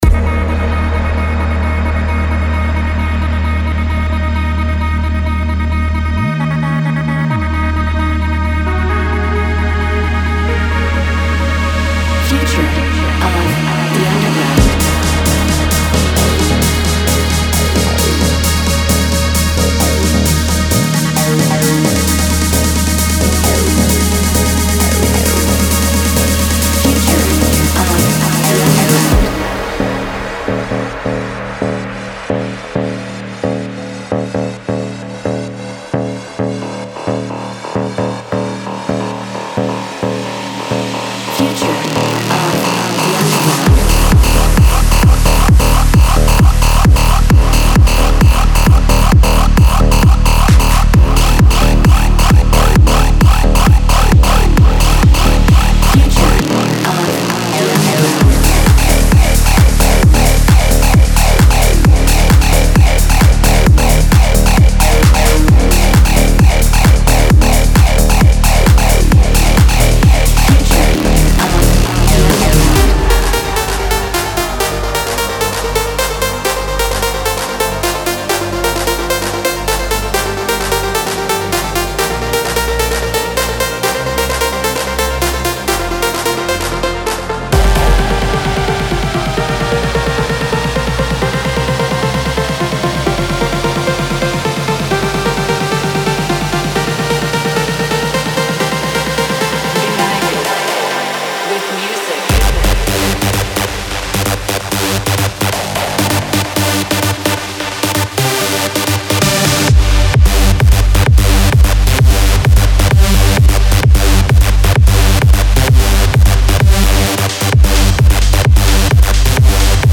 Genre: Techno
Bringing you a harder edge to your Techno productions.